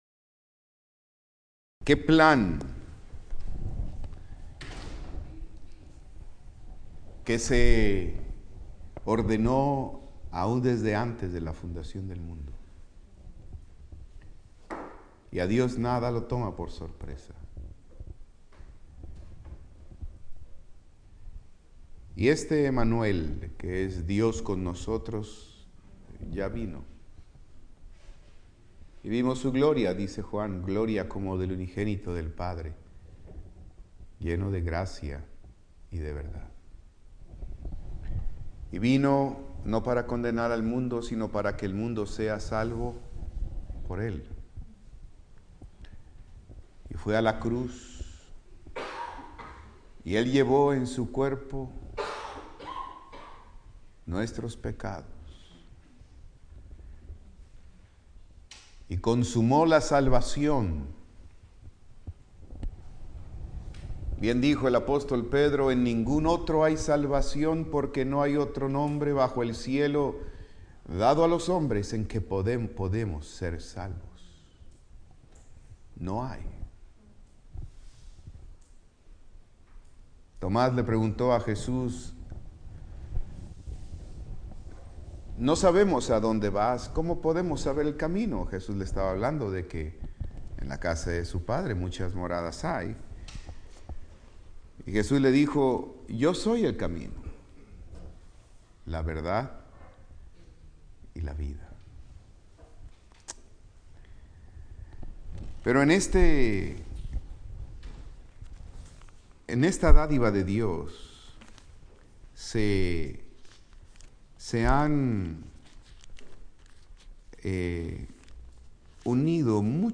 Servicio Vespertino